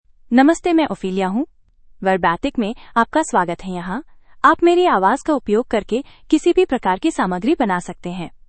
FemaleHindi (India)
OpheliaFemale Hindi AI voice
Ophelia is a female AI voice for Hindi (India).
Voice sample
Listen to Ophelia's female Hindi voice.
Ophelia delivers clear pronunciation with authentic India Hindi intonation, making your content sound professionally produced.